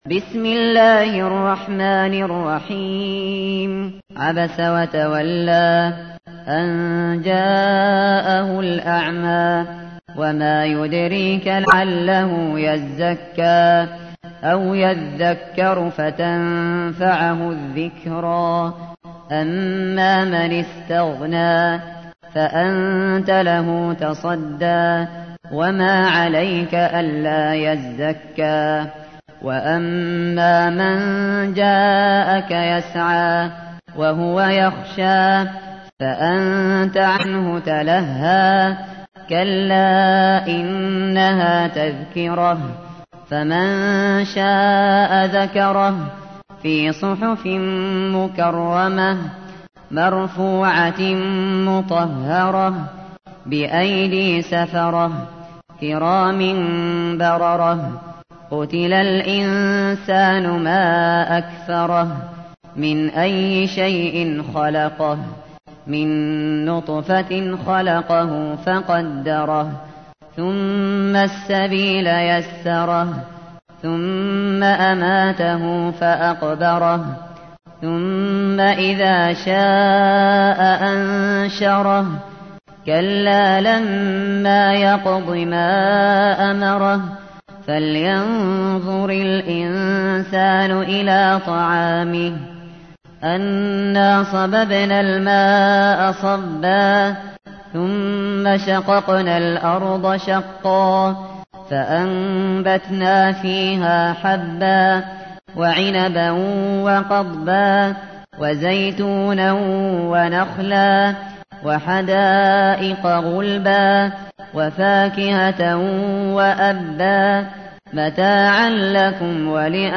تحميل : 80. سورة عبس / القارئ الشاطري / القرآن الكريم / موقع يا حسين